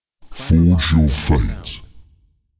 “Forge your fate” Clamor Sound Effect
Can also be used as a car sound and works as a Tesla LockChime sound for the Boombox.